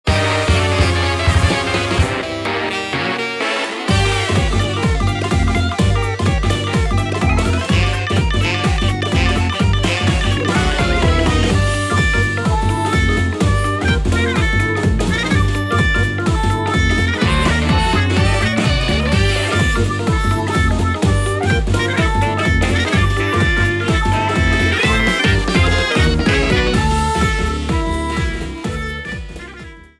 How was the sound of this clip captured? Shortened, applied fade-out